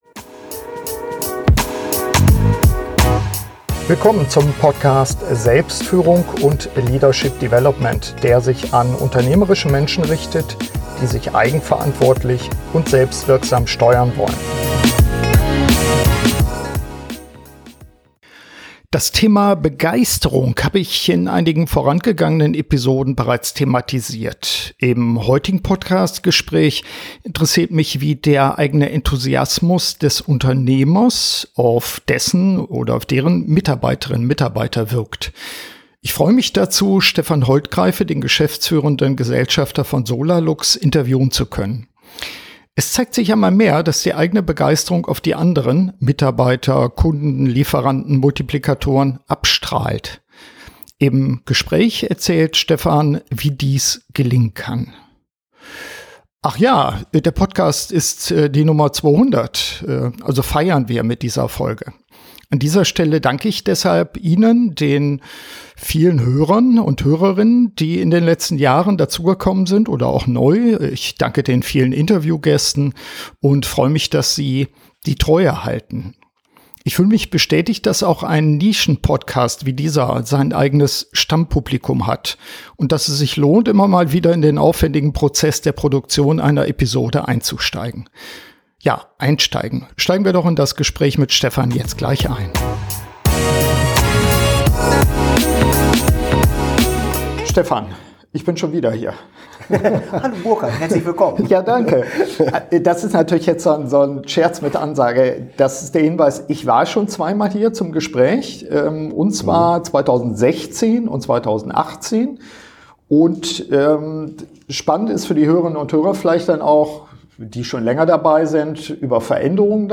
Im heutigen Podcast-Gespräch interessiert mich, wie der eigene Enthusiasmus des Unternehmers auf dessen (oder deren) Mitarbeiter wirkt.